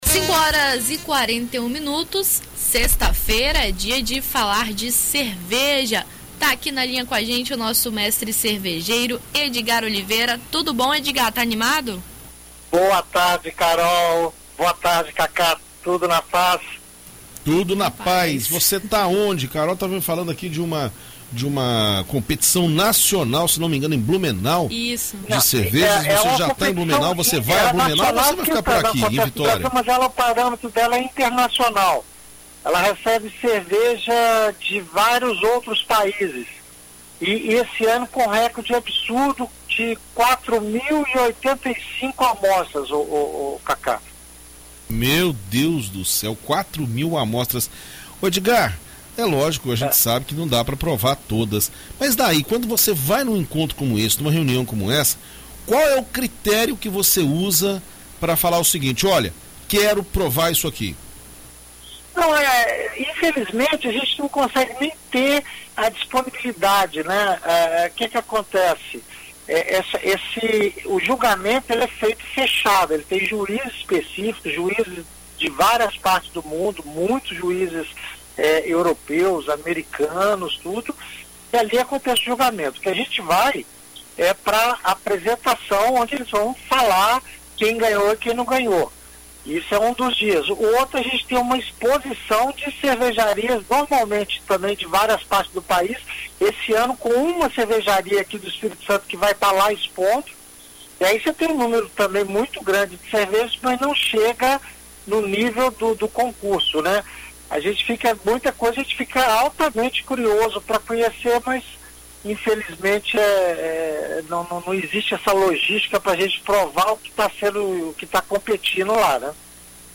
Em entrevista à BandNews FM ES nesta sexta-feira